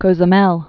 (kōzə-mĕl, s-)